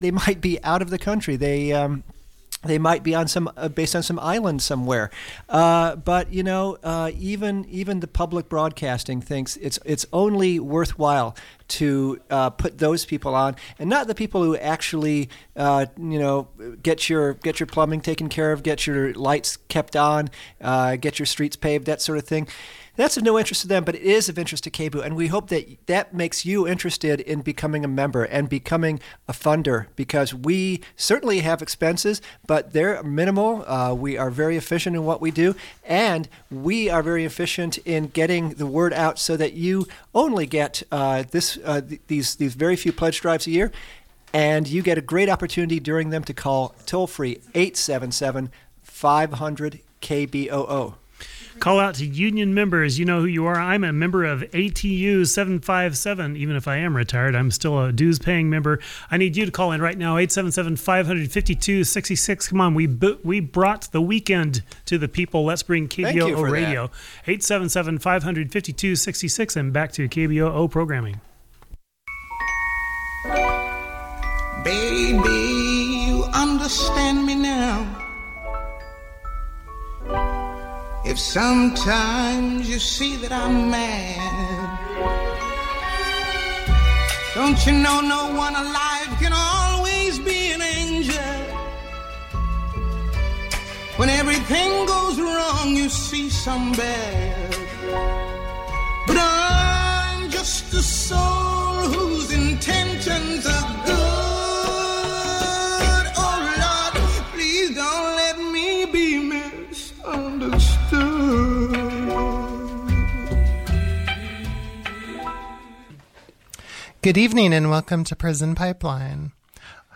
roundtable discussion